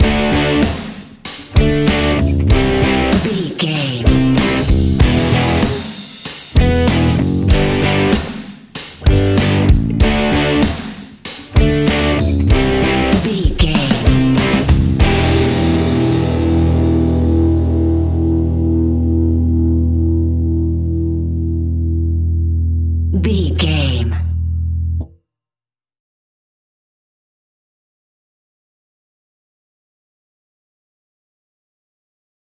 Aeolian/Minor
energetic
driving
happy
bright
electric guitar
bass guitar
drums
hard rock
distortion
heavy drums
distorted guitars
hammond organ